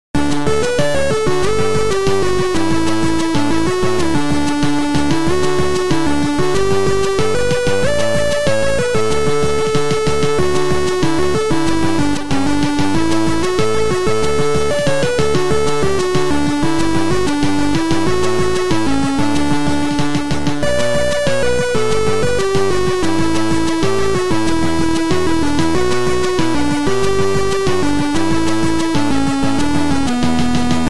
Sound Style: Chip / Sorrow